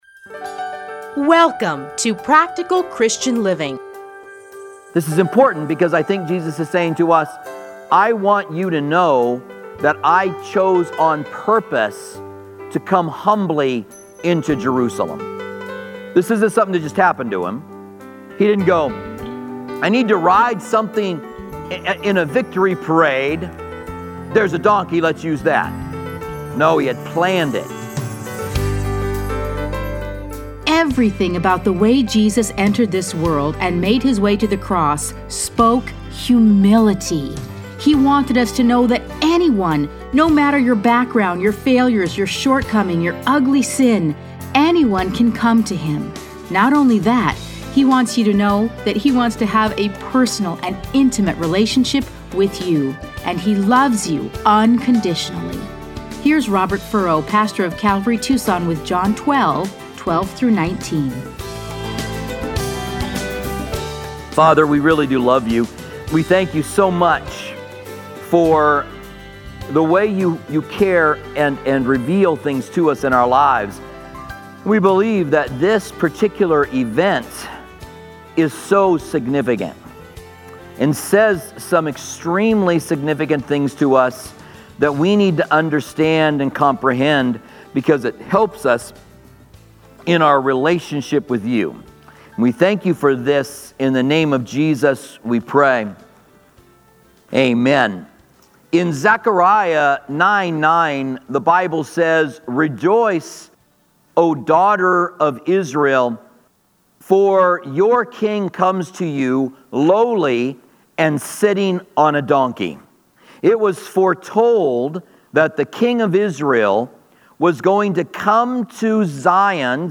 Listen to a teaching from John 12:12-19.